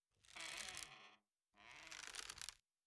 02_孤儿院走廊_秋千声音.wav